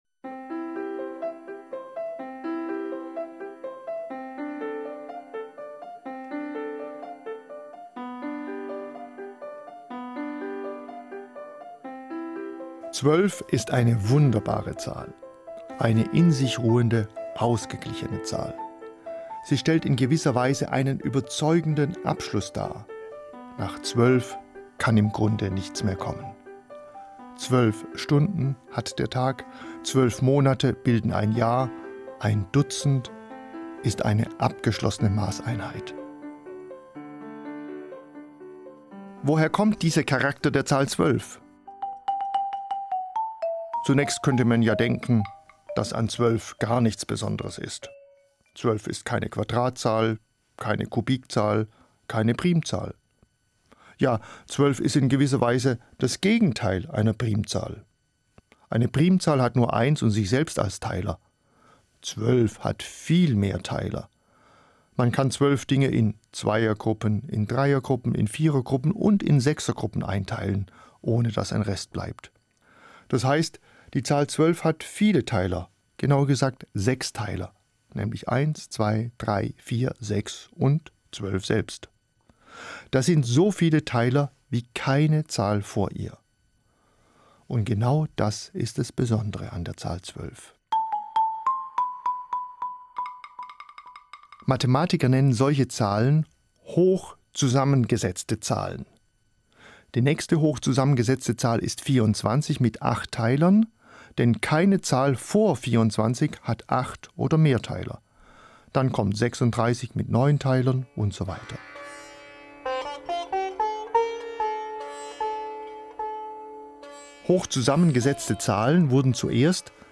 In Wissenswert erzählt er, woher seine Überzeugung rührt.